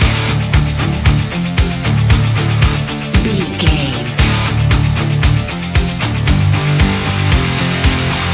energetic, powerful and aggressive hard rock track
Fast paced
Ionian/Major
industrial
driving
groovy
dark
dramatic